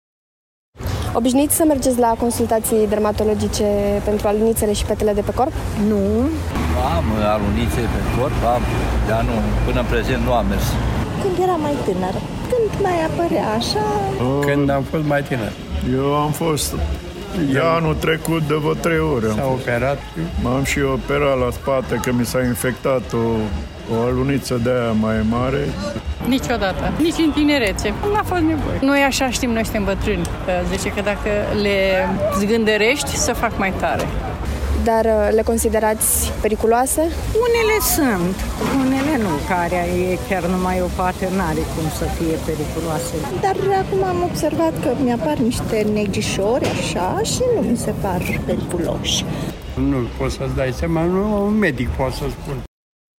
Și brașovenii spun că știu care sunt riscurile la care se expun, mai ales vara, când stau mult la soare, dar puțini dintre ei apelează la ajutor de specialitate:
Vox-dermato.mp3